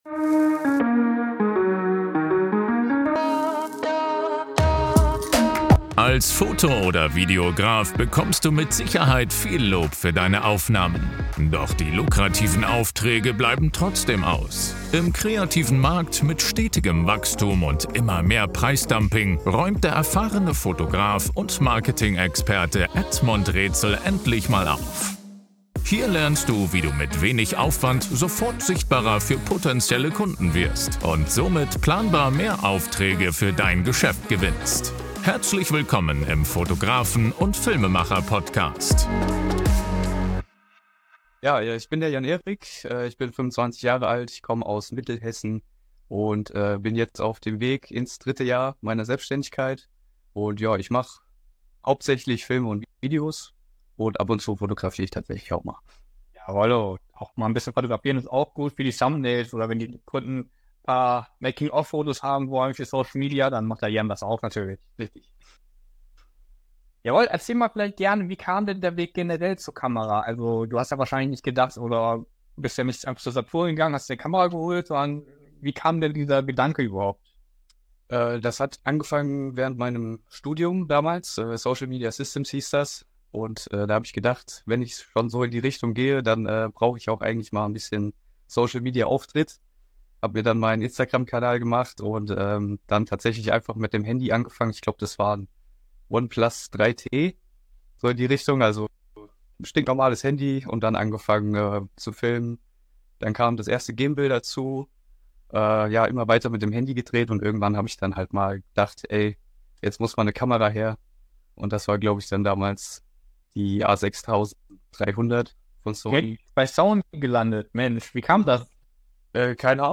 Technik Talk